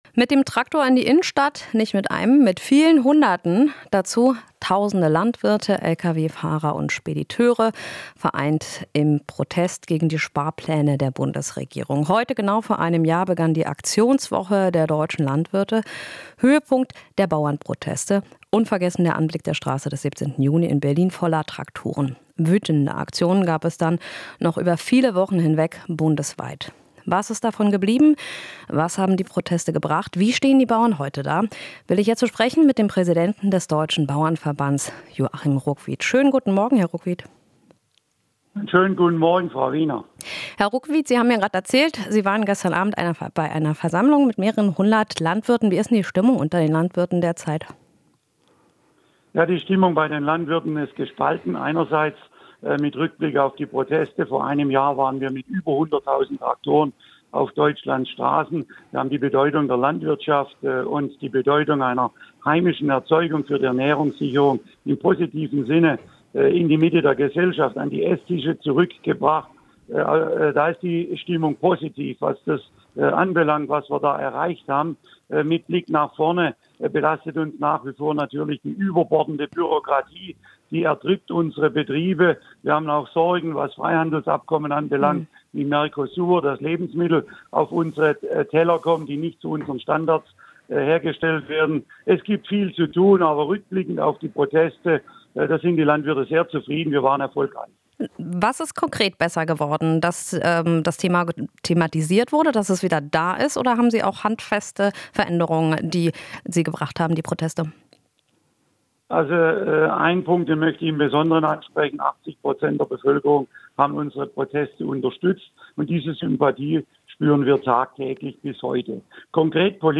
Interview - Bauernverband fordert: Wettbewerbsfähigkeit innerhalb der EU wieder herstellen